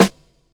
Snares
WF_SNR (1).wav